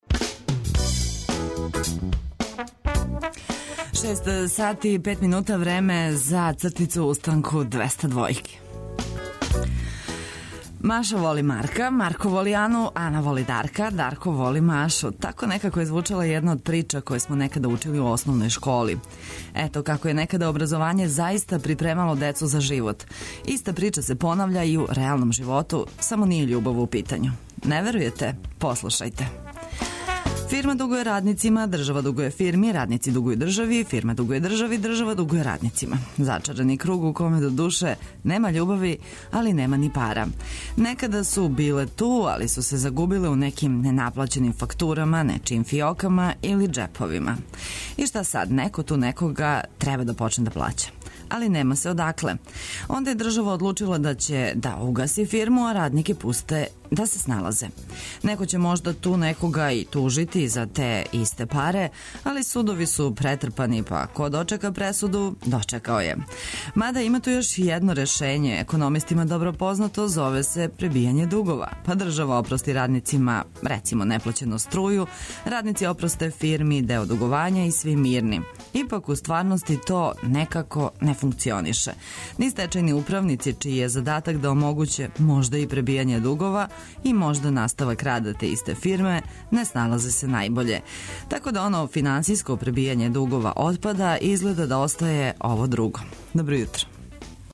Петак је дан за... устајање са осмехом, испијање кафе у ритму добре музике и ослушкивање викенда који само што није... Будимо се заједно од шест до девет на таласима Београда 202.